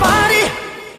Trechinho da música